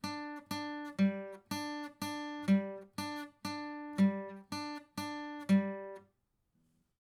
Acoustic guitar
Microphones Used: AKG 411
AKG 451
Master Tempo Track : 120 BPM
acoustic_ptrn_45.wav